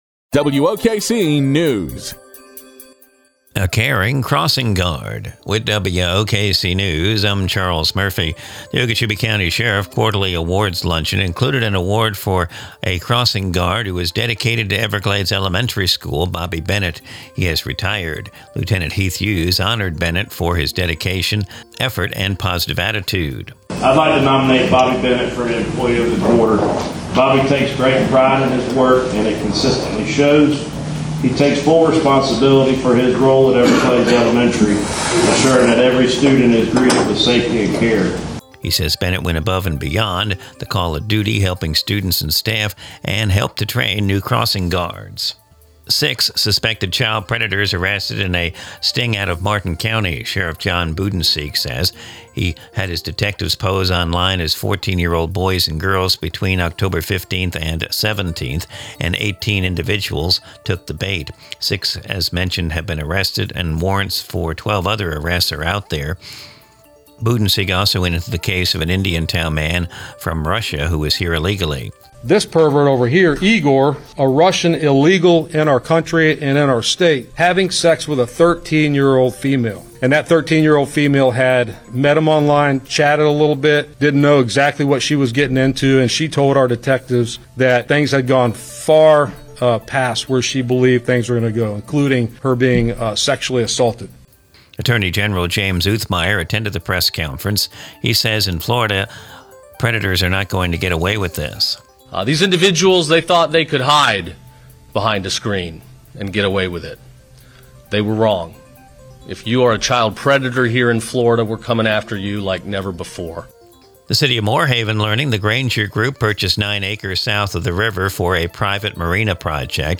Recorded from the WOKC daily newscast (Glades Media).